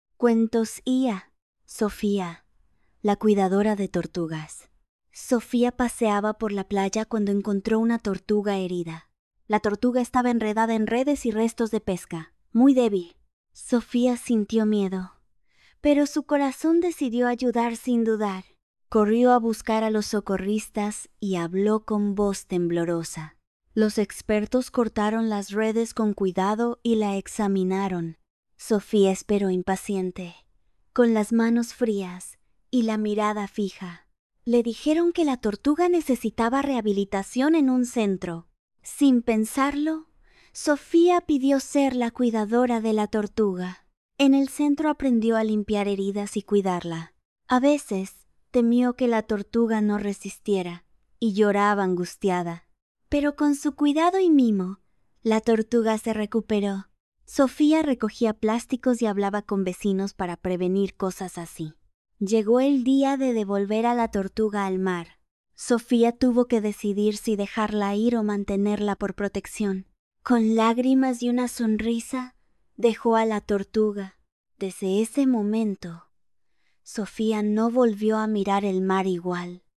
Audiolibro Narrado